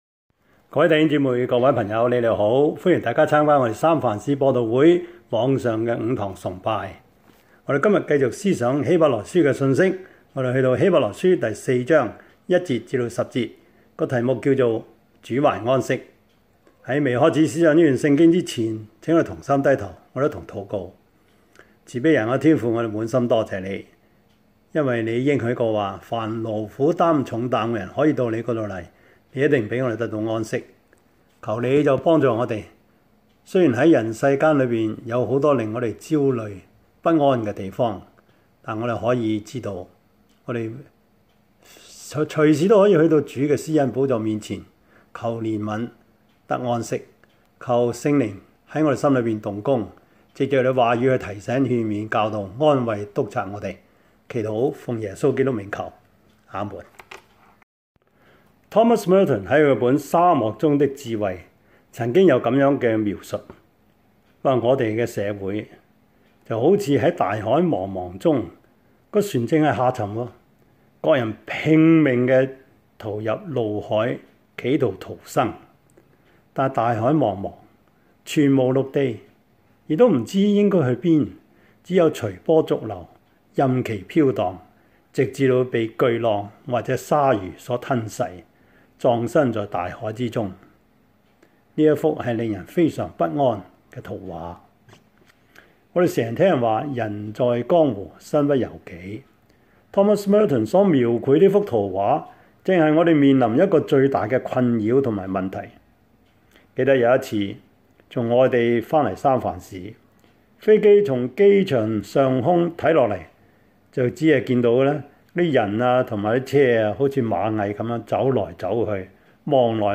Topics: 主日證道 « 人手不足 第六十二課:十九世紀的教會音樂 »